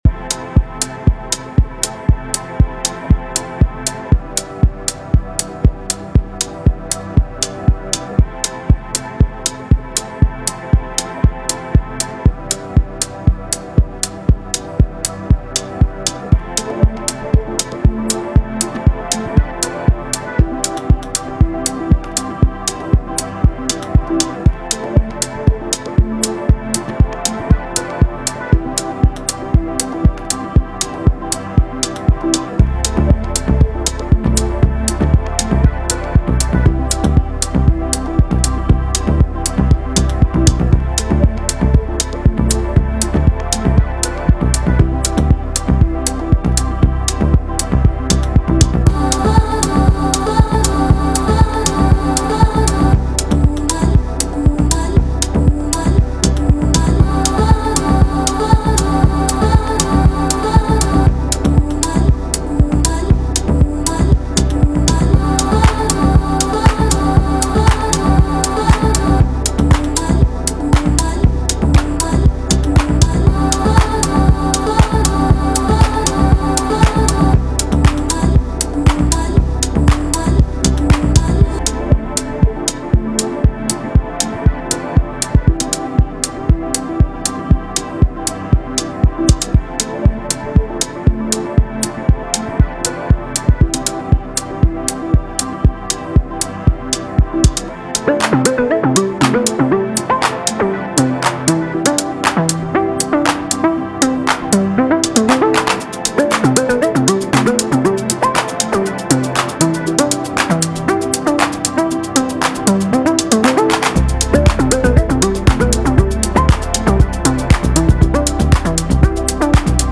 These are the master stems from the Tracker+ so not expertly mixed by any stretch. I then ran though a couple of mix & master plugins, just so I don’t completely embarrass myself here.